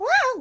toadette_whoa.ogg